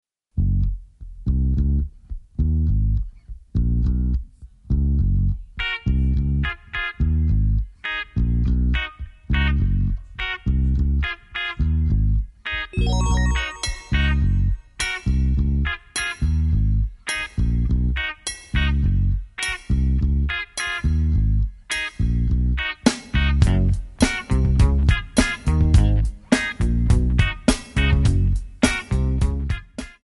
F#
MPEG 1 Layer 3 (Stereo)
Backing track Karaoke
Pop, Rock, Oldies, 1960s